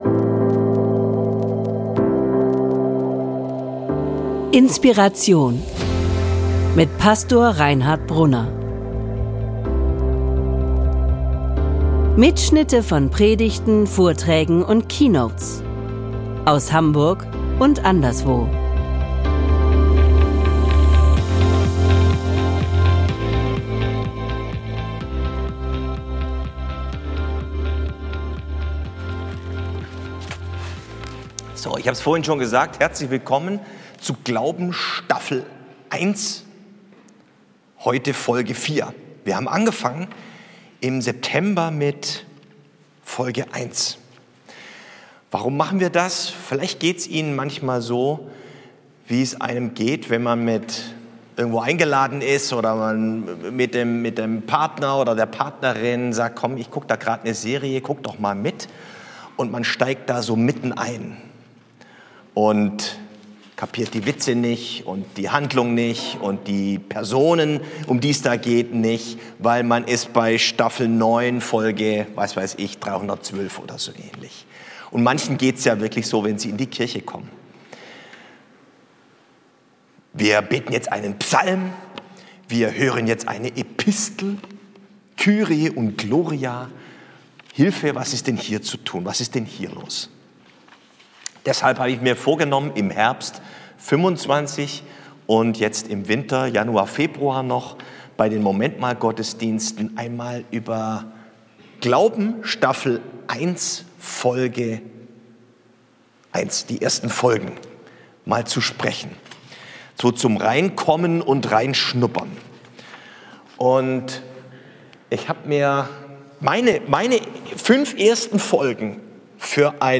Bei den Momentmal-Gottesdiensten im Herbst 2025 werde ich ganz einfach und praktisch darüber sprechen, wie man anfangen kann zu glauben. Worum geht es überhaupt beim Glauben?